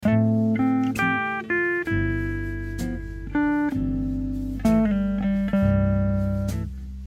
L’insistance par répétition de la Quinte en triolet est introduite par la note diatonique supérieure en appogiature.